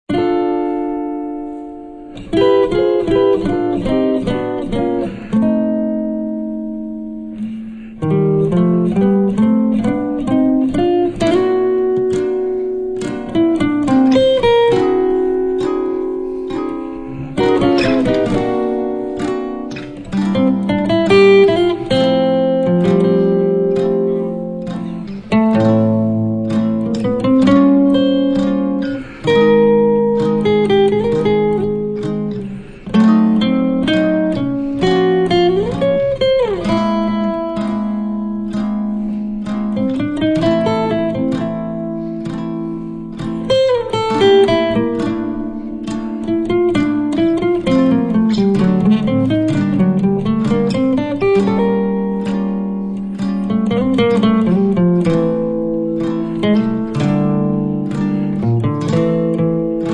chitarra